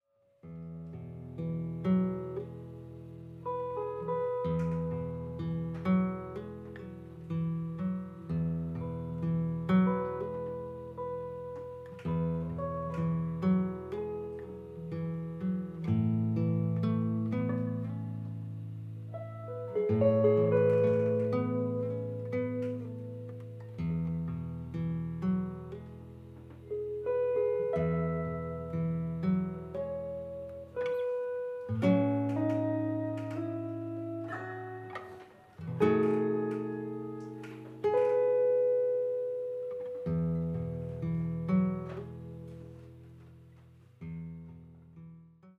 深みのあるギター・サウンド。囁くような歌声に呼び起こされるのはいつかの思い出。